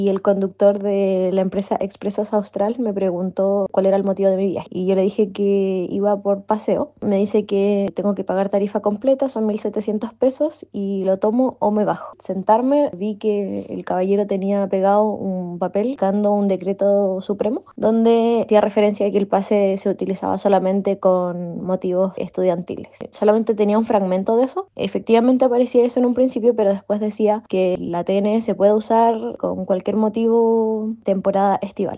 En ese contexto, una usuaria quien señaló que mientras iba camino al sector Lenca en la Carretera Austral, el conductor de un bus le cobró la tarifa completa porque el viaje no era con motivos de estudio.